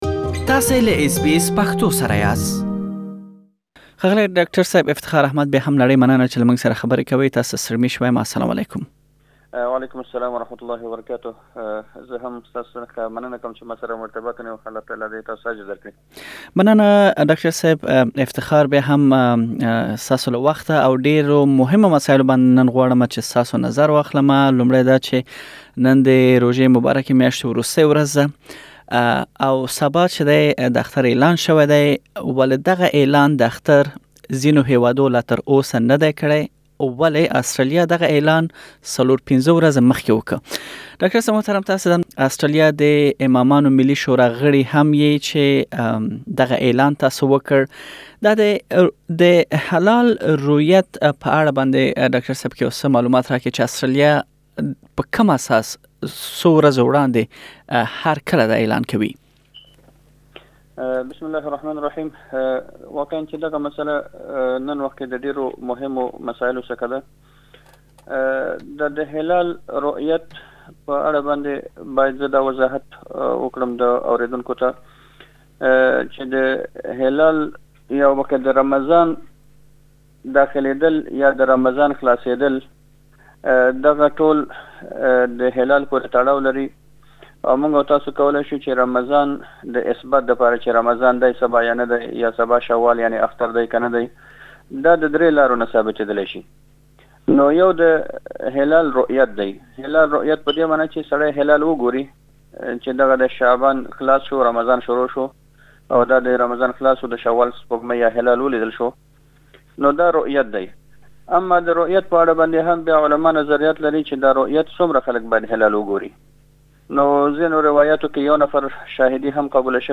د روژې پيل او پای او بيا د اخترونو د ورځې اعلان د مسلمانانو ترمينځ تل تاوده بحثونه راپاروي، ولې دا مساله مو ډيره کې اسټراليا کې د يو ديني عالم سره مرکه کې د علم په رڼا کې روښانه کړې.